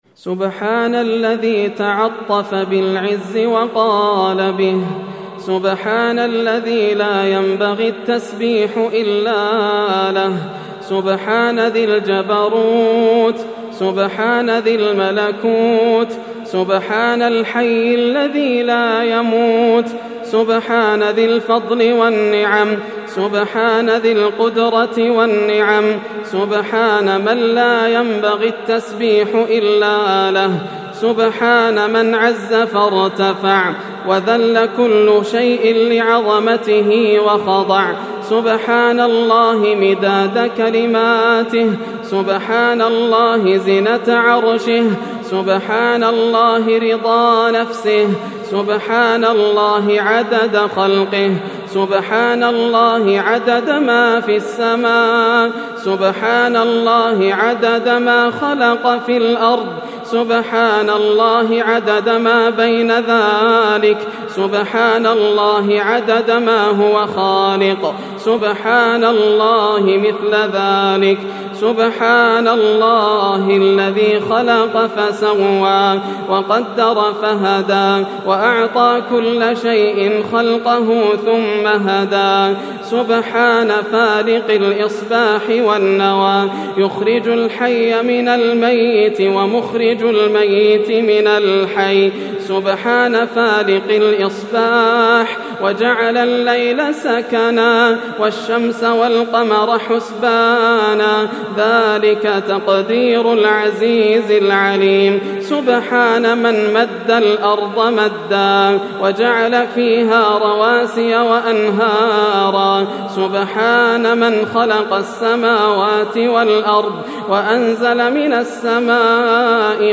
دعاء ليلة 27 رمضان 1431هـ > الأدعية > رمضان 1431هـ > التراويح - تلاوات ياسر الدوسري